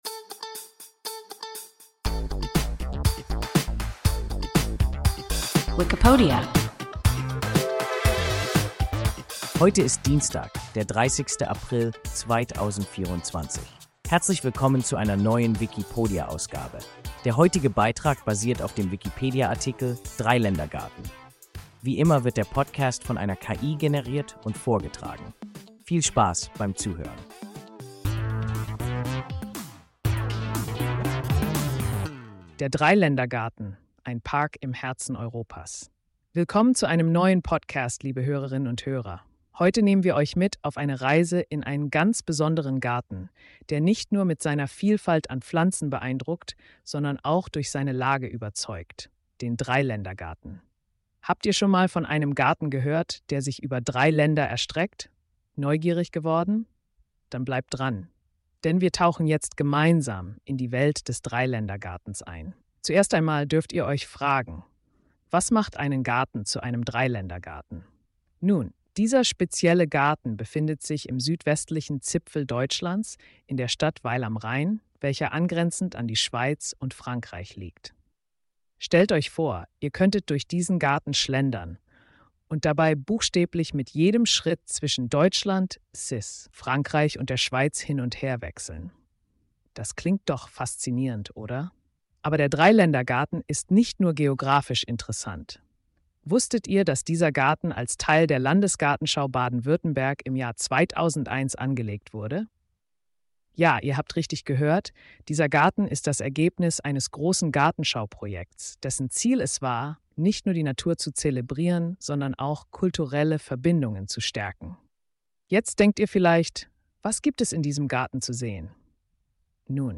Dreiländergarten – WIKIPODIA – ein KI Podcast